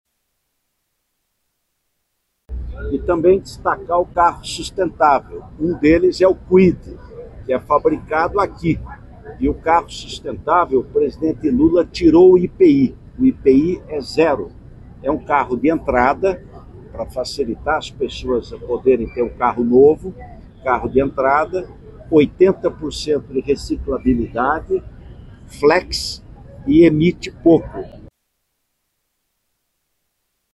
No Complexo Ayrton Senna, um dos modelos fabricados que é considerado sustentável é o Renault Kwid, como lembrado pelo vice-presidente e ministro.